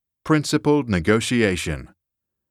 Principled Negotiation [prin-suh-puh ld] [ni-goh-shee-ey-shuh n]